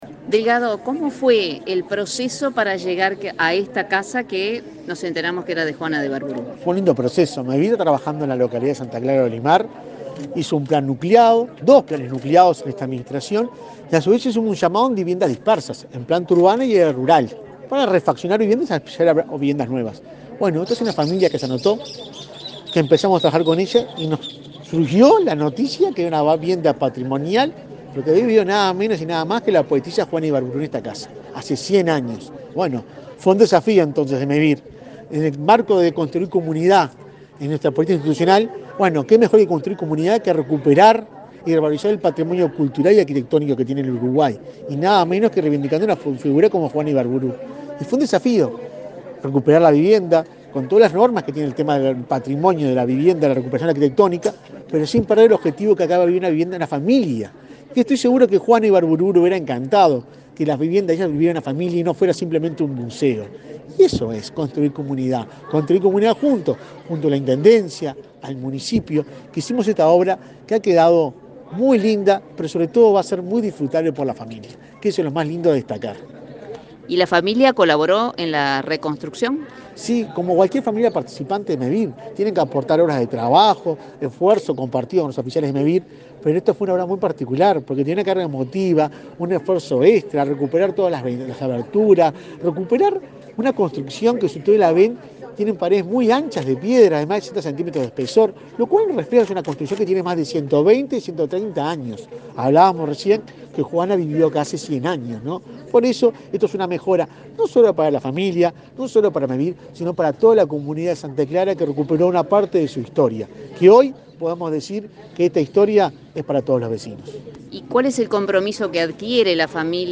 Entrevista al presidente de Mevir, Juan Pablo Delgado
El presidente de Mevir, Juan Pablo Delgado, dialogó con Comunicación Presidencial luego de participar en la inauguración de una obra de reciclaje en